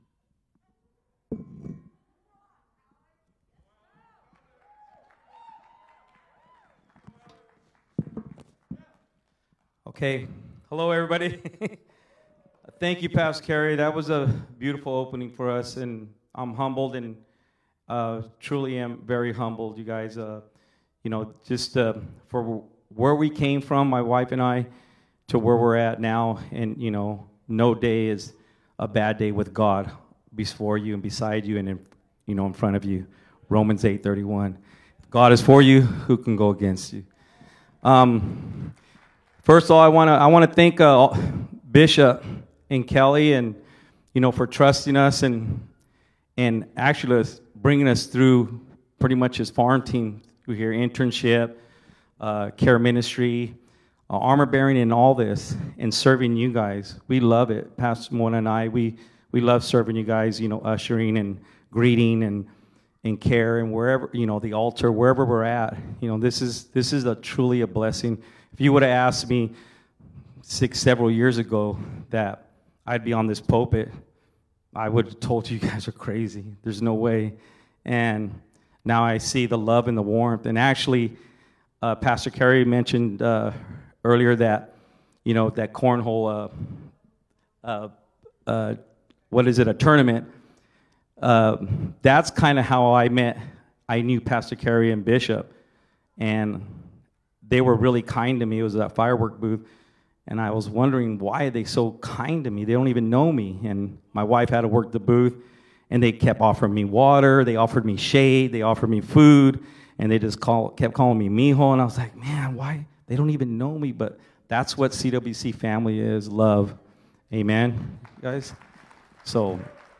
Sermons | CWC LIFE Manteca CA